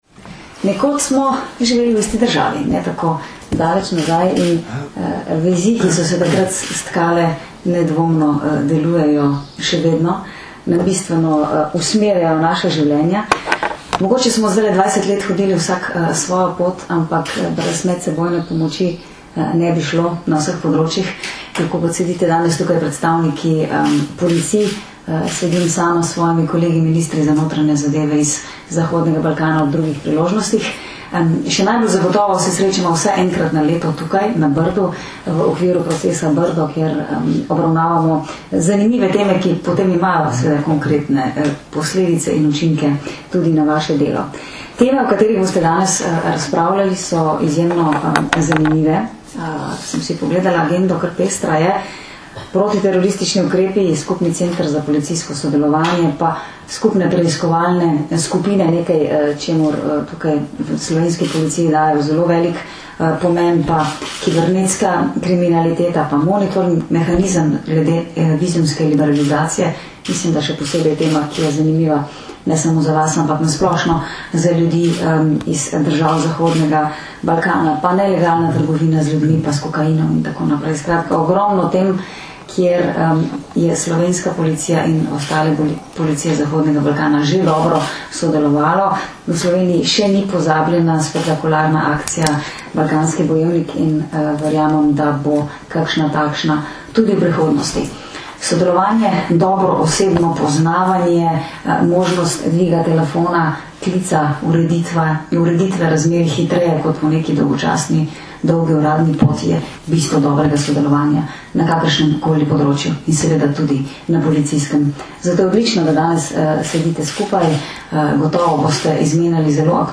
Na Brdu pri Kranju se je danes, 19. maja 2011, dopoldne s pozdravnim nagovorom generalnega direktorja policije Janka Gorška začelo dvodnevno srečanje šefov policij Zahodnega Balkana.
Zvočni posnetek nagovora Katarine Kresal (mp3)